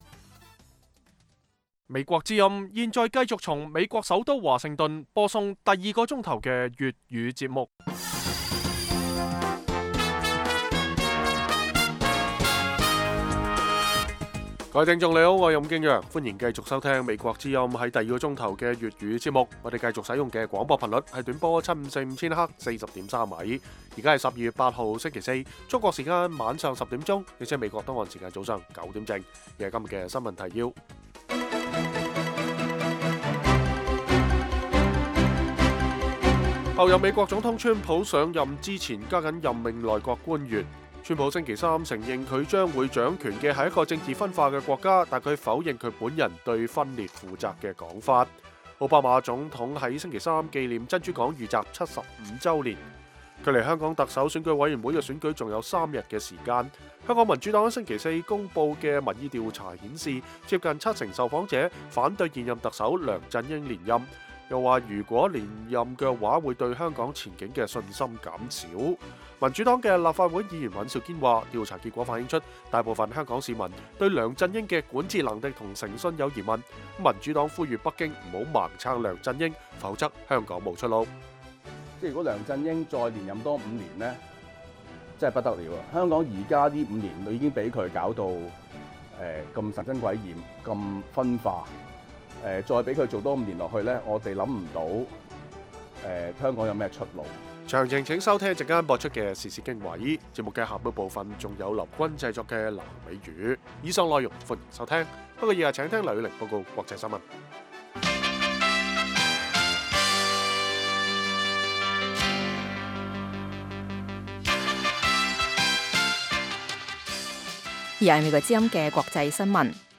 北京時間每晚10－11點 (1400-1500 UTC)粵語廣播節目。內容包括國際新聞、時事經緯、英語教學和社論。